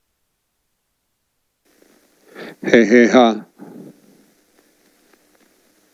Meditazione vibrazionale sul”amore incondizionato”